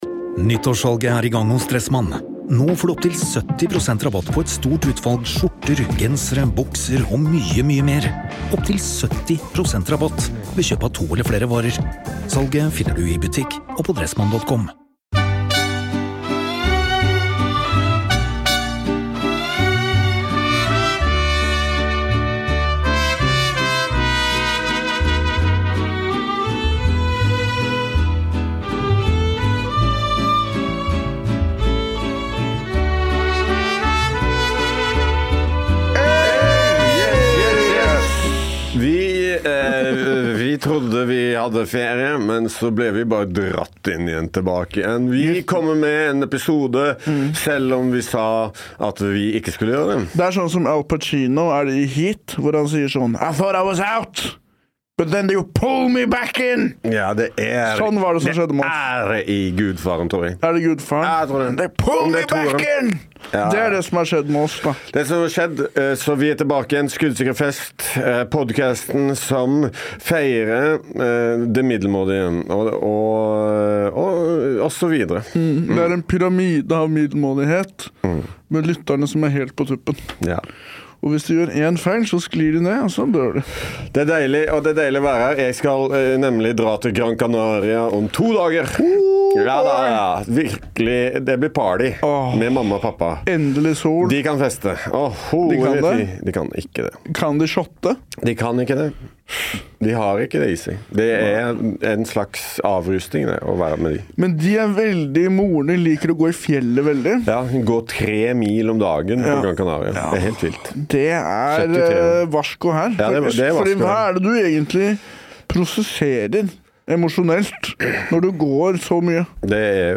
Hver fredag (og innimellom tirsdag med gjest) møtes de i studio for å hylle middelmådigheten.